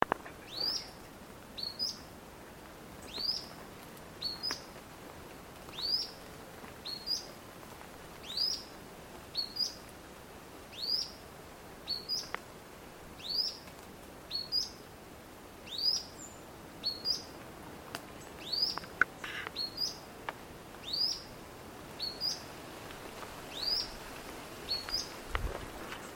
Grey-bellied Spinetail (Synallaxis cinerascens)
Un ejemplar vocalizando bajo la lluvia.
Condition: Wild
Certainty: Recorded vocal